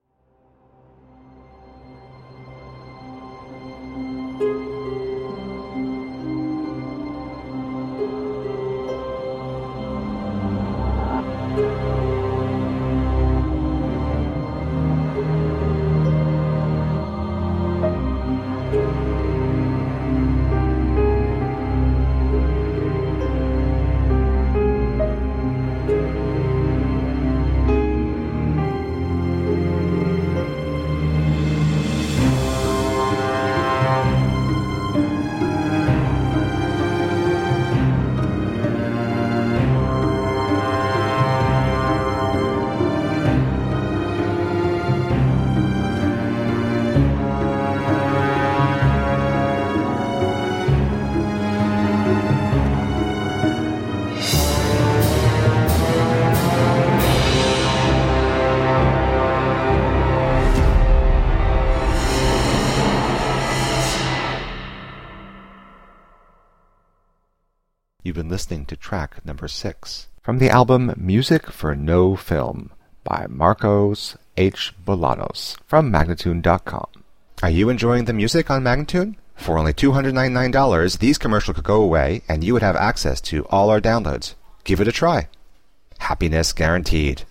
Atmospheric, instrumental soundtracks for daydreaming.